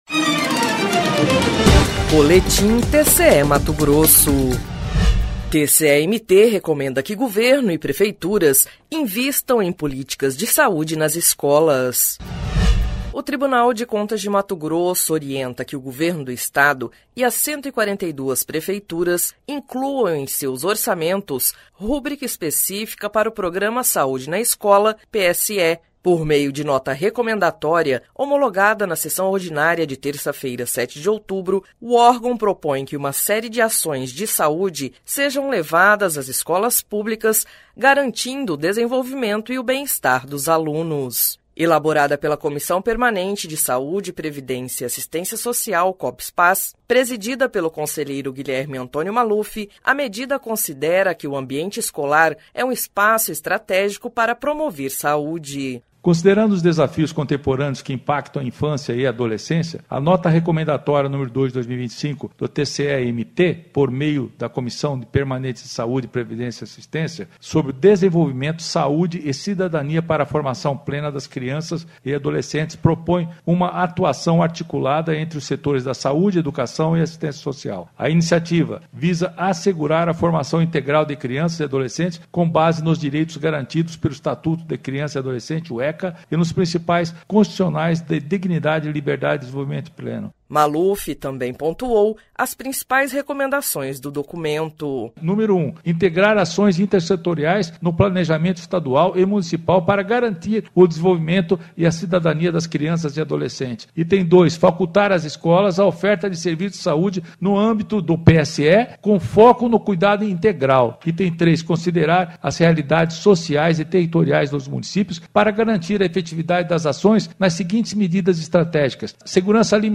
Sonora: Guilherme Antonio Maluf – conselheiro presidente da COPSPAS